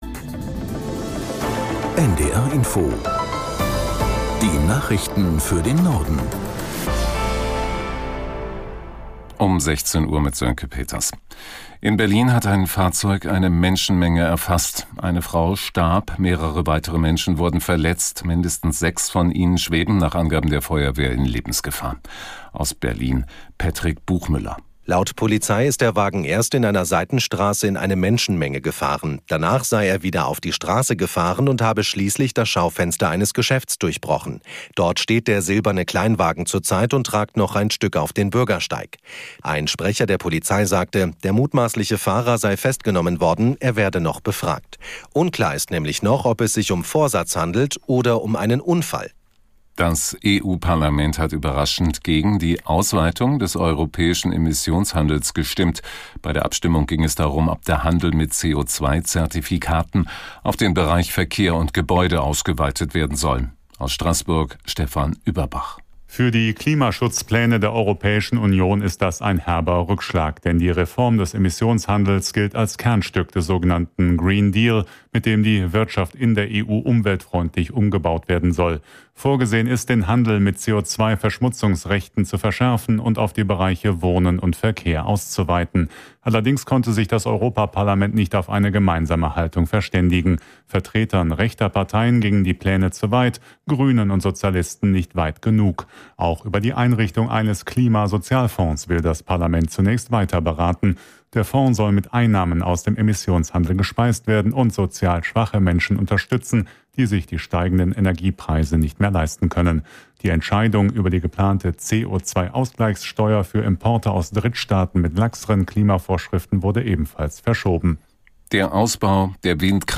Nachrichten - 01.07.2022